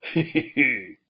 Talking Ben Hehehe Sound Button | Sound Effect Pro
Instant meme sound effect perfect for videos, streams, and sharing with friends.